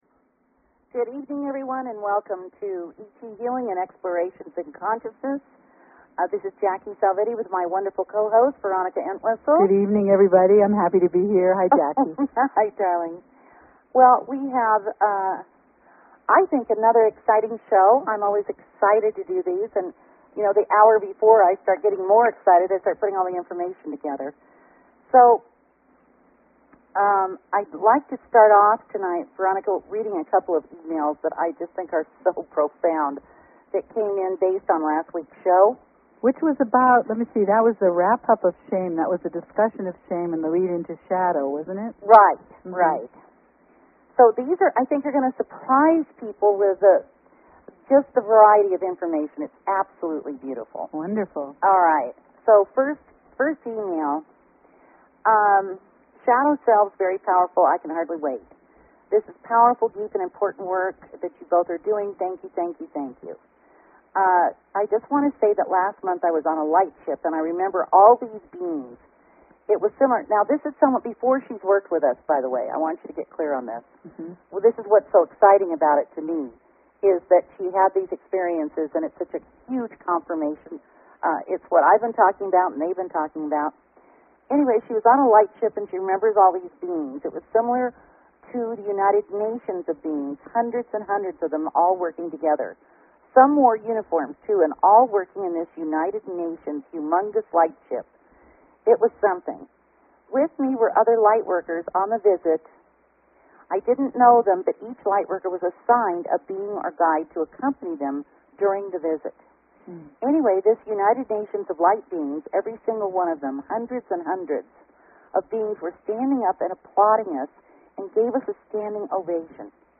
Talk Show Episode, Audio Podcast, ET_Healing and Courtesy of BBS Radio on , show guests , about , categorized as